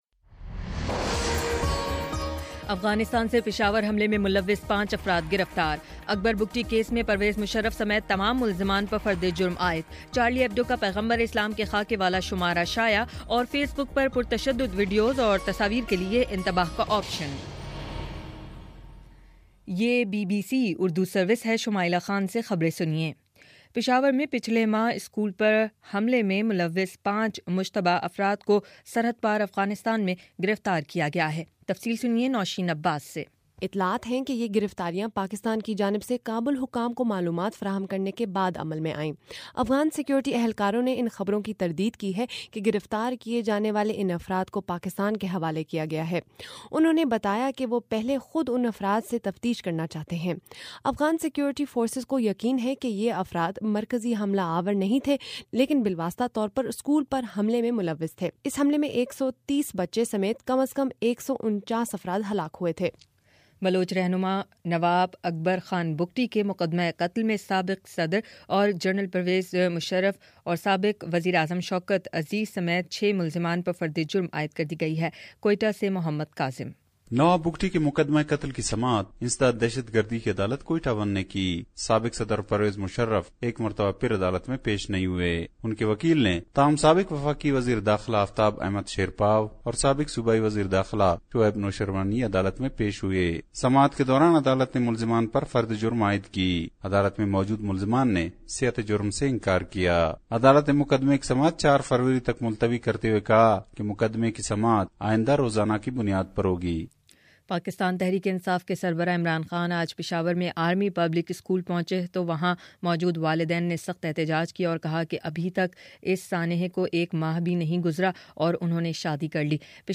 جنوری14: شام چھ بجے کا نیوز بُلیٹن